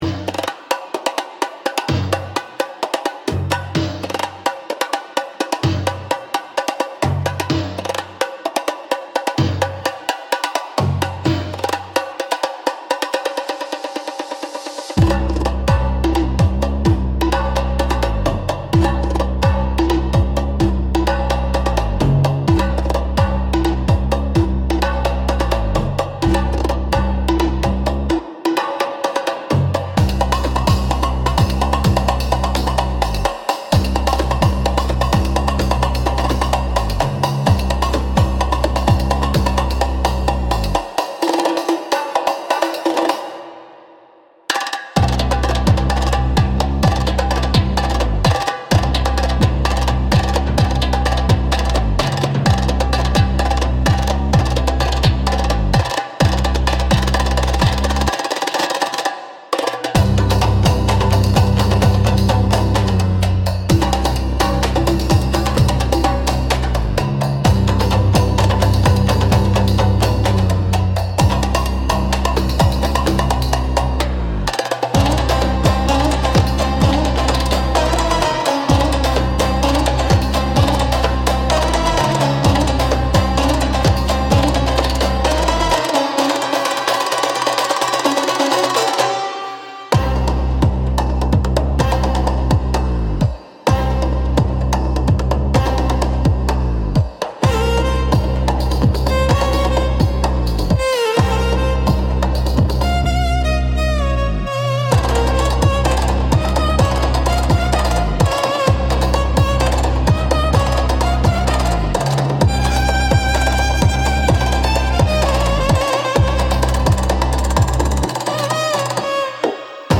Instrumental - Snake Dance Ritual